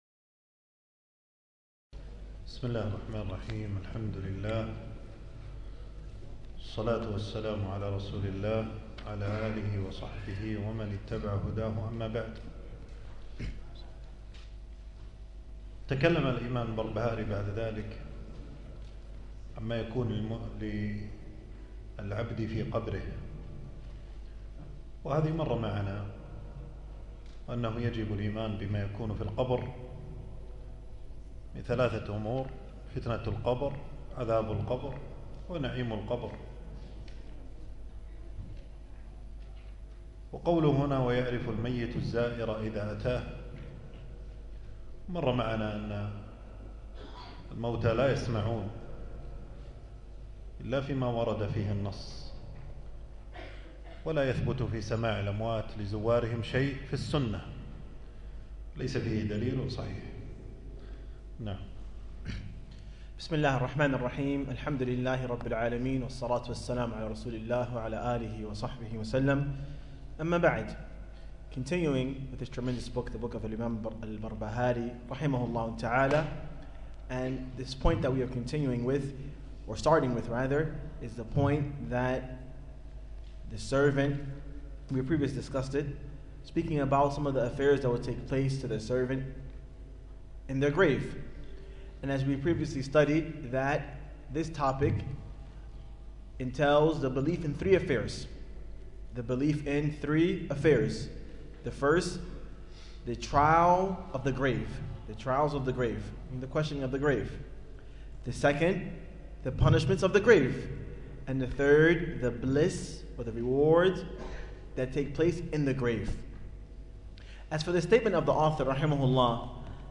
تنزيل تنزيل التفريغ العنوان: شرح، شرح السنة للبربهاري. (الدرس الرابع والأخير) ألقاه
المكان: درس ألقاه يوم السبت 18 جمادى الأول 1447هـ في مسجد السعيدي.